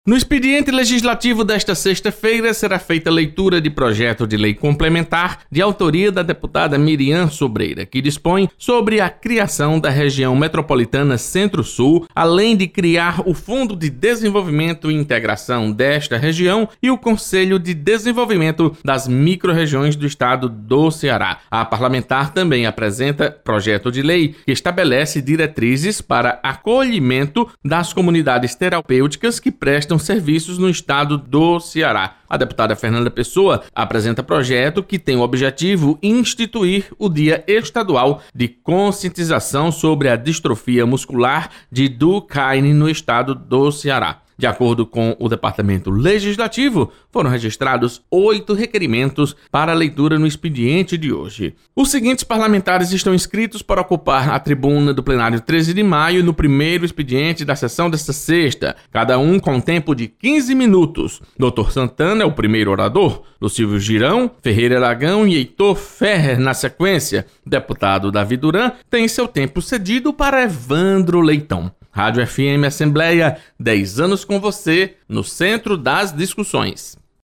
• Fonte: Agência de Notícias da Assembleia Legislativa